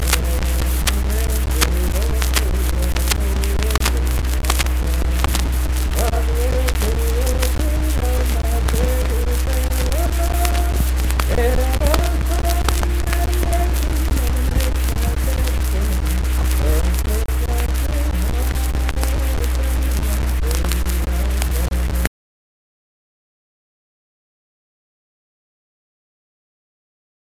Unaccompanied vocal music
Verse-refrain 1(8). Performed in Kanawha Head, Upshur County, WV.
Voice (sung)